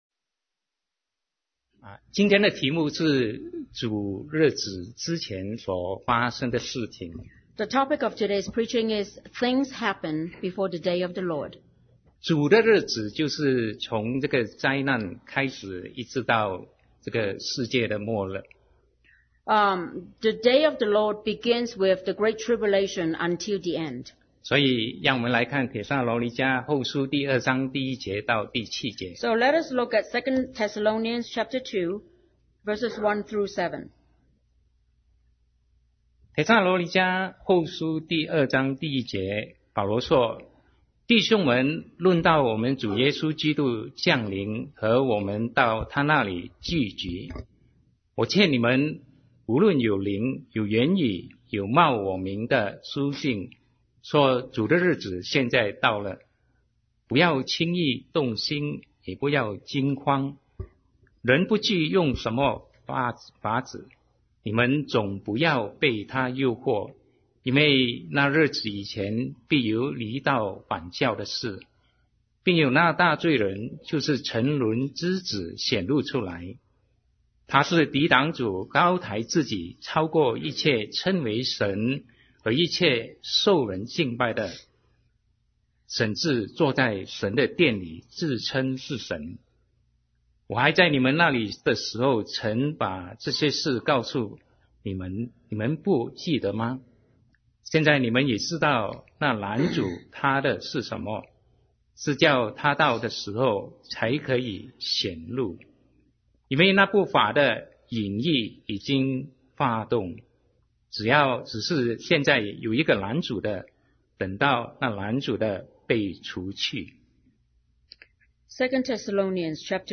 Sermon 2017-09-17 Things Happen before the Day of the Lord